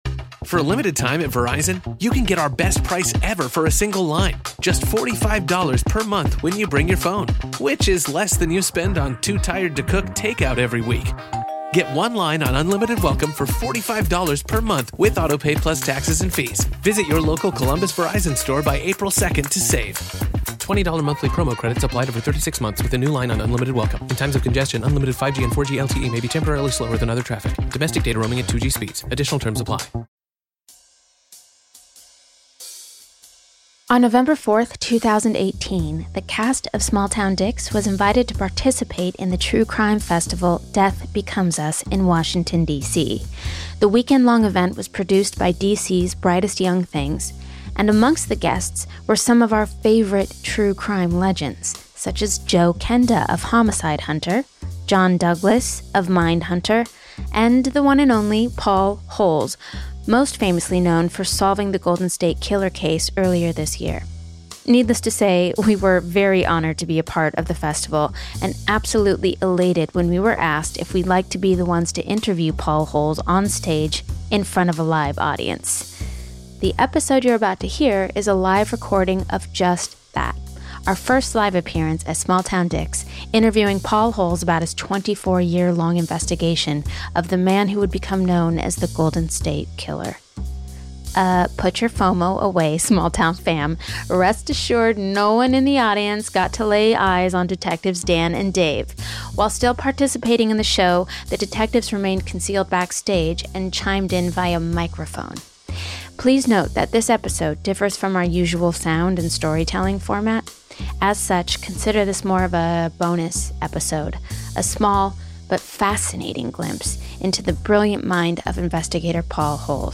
Live Show with Investigator Paul Holes
On Nov 4th the Small Town Dicks team appeared in their first live show at the Death Becomes Us true crime festival.